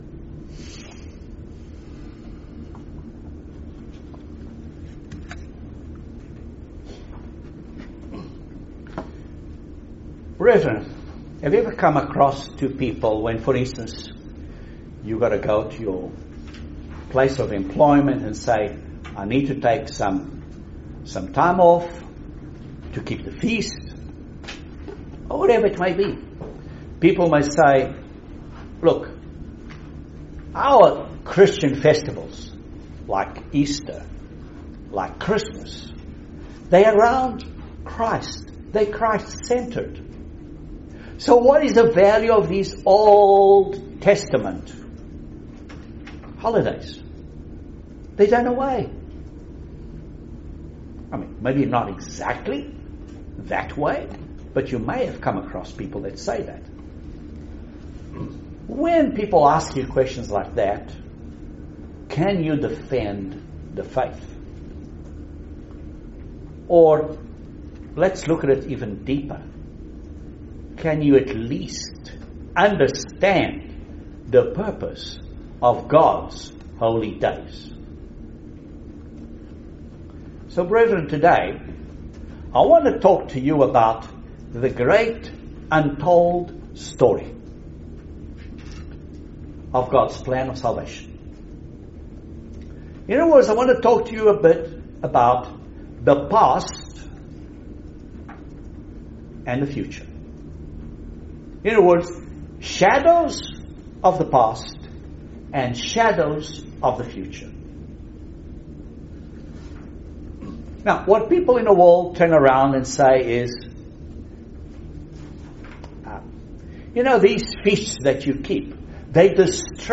Excellent Sermon on the true message of the Gospel of Jesus Christ. Has most of the world turned to a different Gospel than the one Christ preached? what does the Bible tell us about the Gospel that Jesus preached?